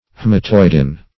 Haematoidin \H[ae]m`a*toid"in\, n.